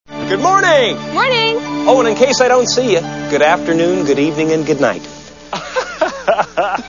The Truman Show Movie Sound Bites